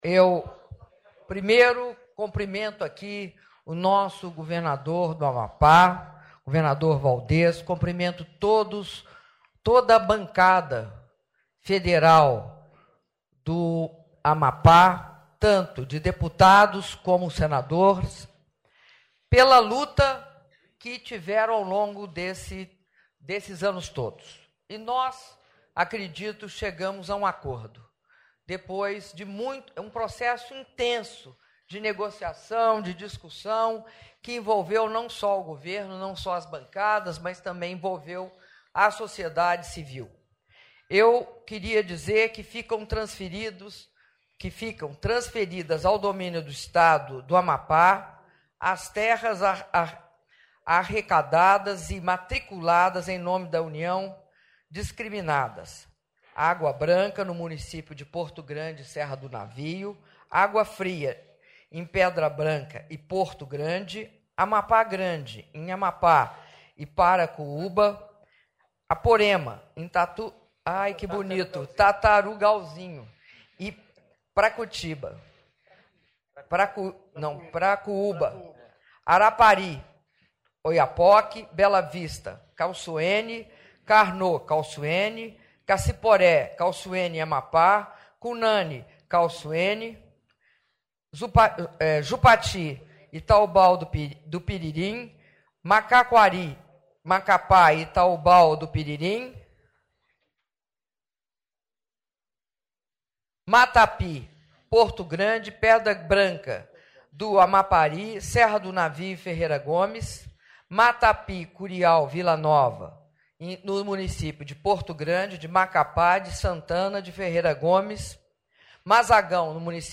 Áudio do discurso da presidenta da República, Dilma Rousseff, na assinatura do decreto que regulamenta a transferência do domínio do estado do Amapá de terras pertencentes à União - Brasília/DF (04min08s)